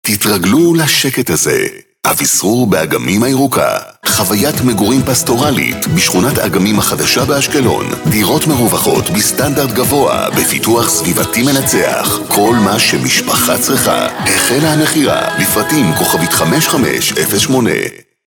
תשדירי רדיו לדוגמה